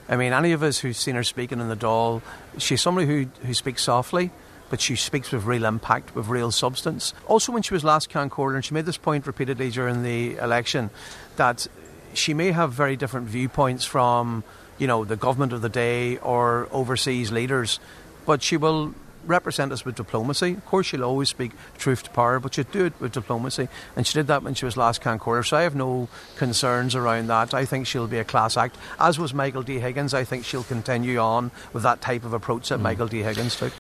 Sinn Féin TD Padraig Mac Lochlainn says he believes Catherine Connolly will carry on President Michael D. Higgins’ legacy: